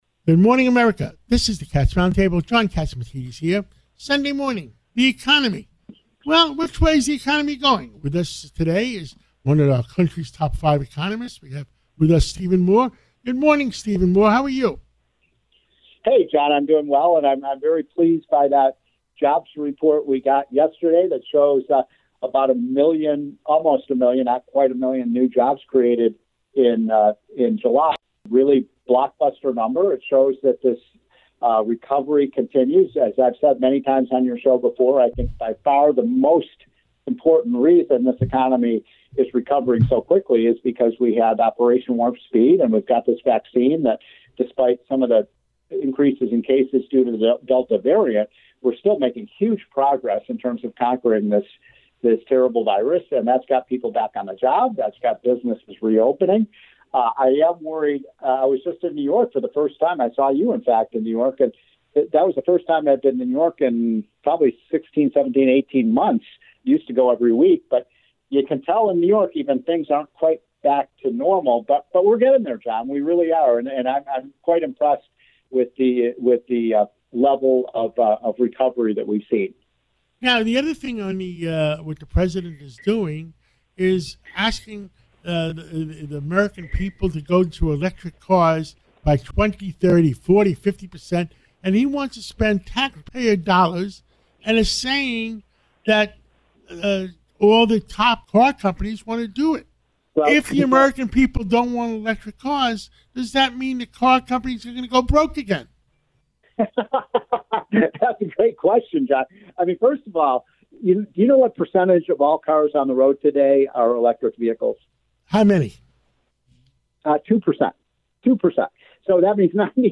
Former Trump economist Stephen Moore on Sunday slammed President Joe Biden's "catastrophic" spending bill and electric car plan, telling radio host John Catsimatidis the auto industry will be "connected at the hip with" the U.S. government as a result.